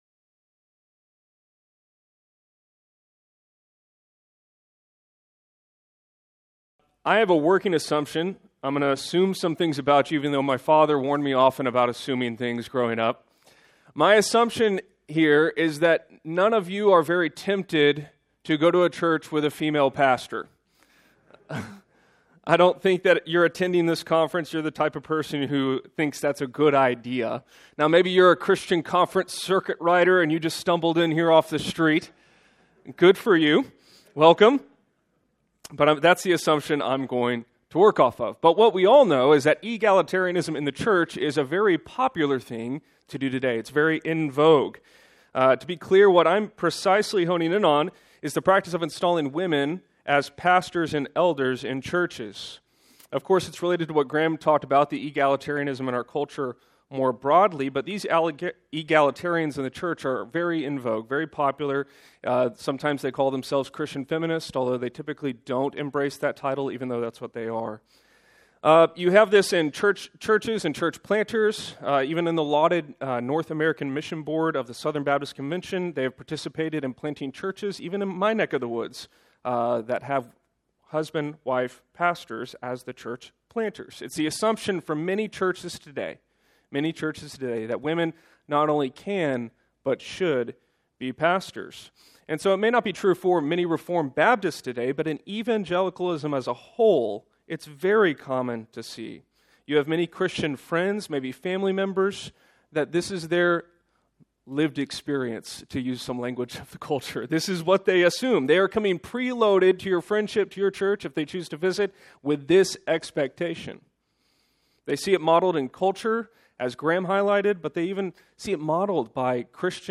Quickened" 2026 National Founders Pre-Conference in Cape Coral, Florida.
Sermons